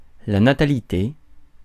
Prononciation
Prononciation France: IPA: [la na.ta.li.te] Accent inconnu: IPA: /na.ta.li.te/ Le mot recherché trouvé avec ces langues de source: français Les traductions n’ont pas été trouvées pour la langue de destination choisie.